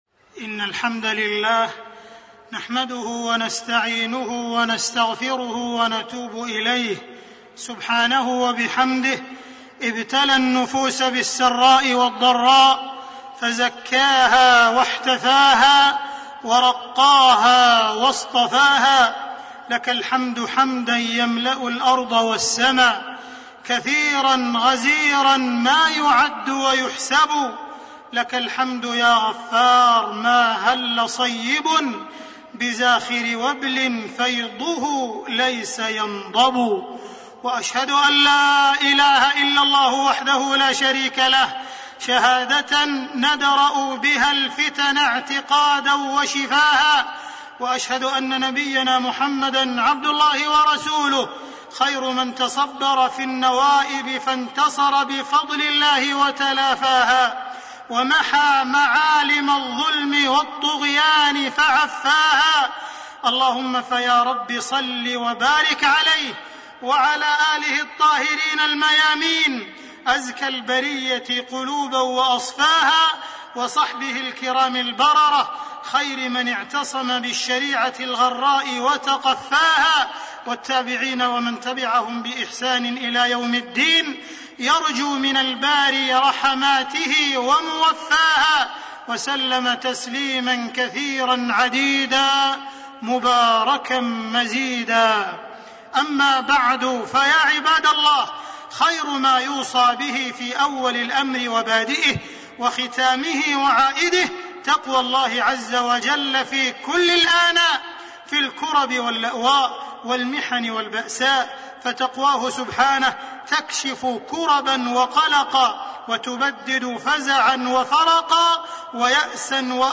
تاريخ النشر ٧ جمادى الأولى ١٤٣٣ هـ المكان: المسجد الحرام الشيخ: معالي الشيخ أ.د. عبدالرحمن بن عبدالعزيز السديس معالي الشيخ أ.د. عبدالرحمن بن عبدالعزيز السديس سوريا الفرج قادم